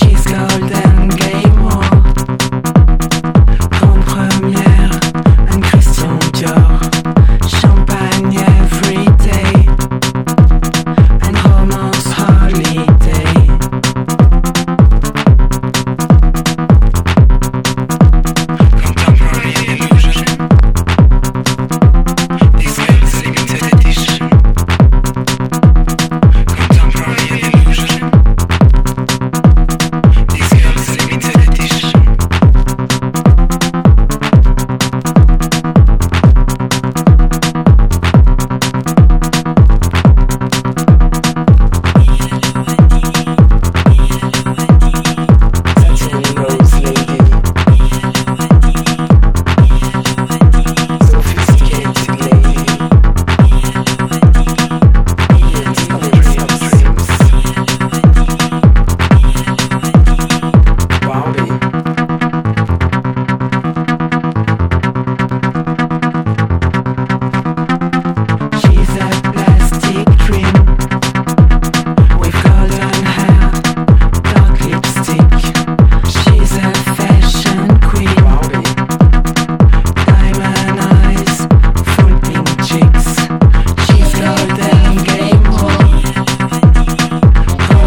ELECTRONICA / MINIMAL SYNTH POP
ドリーミー・シンセ・ポップ・サイケな16年作！
ミニマル・アンビエントな